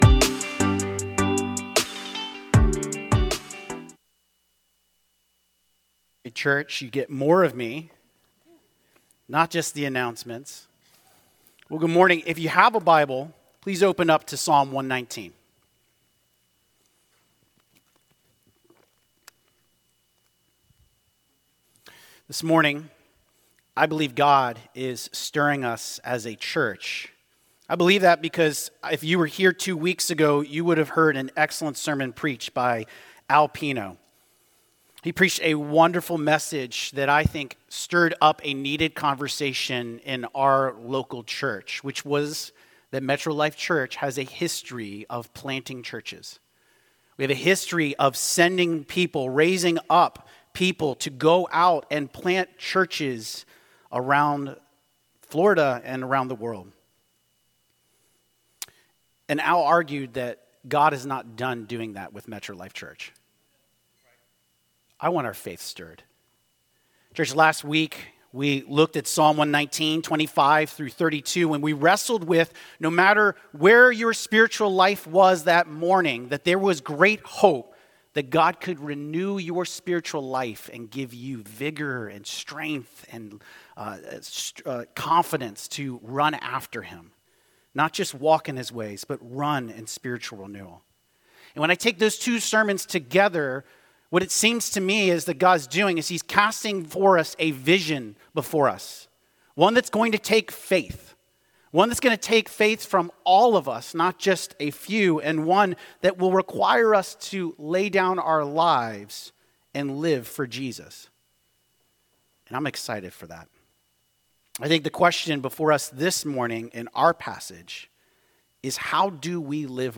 This page contains the sermons and teachings of Metro Life Church Casselberry Florida